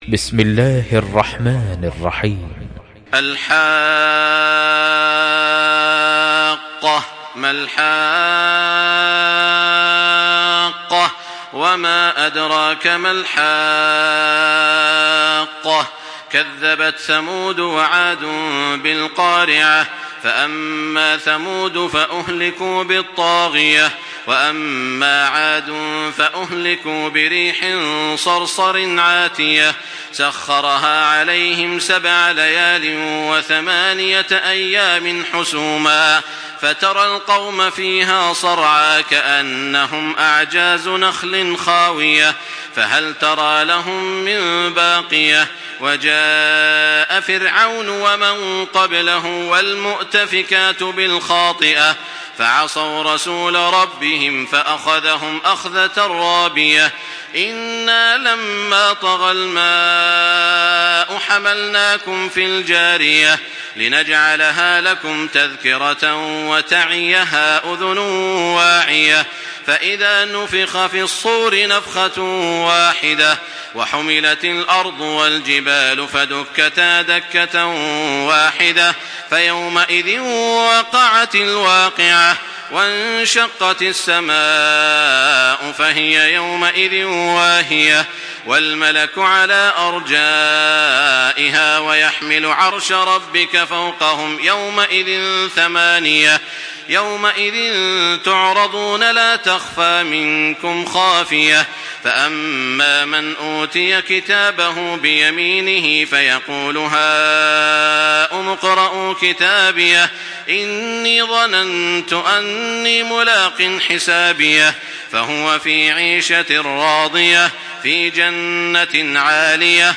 Surah الحاقه MP3 by تراويح الحرم المكي 1425 in حفص عن عاصم narration.
مرتل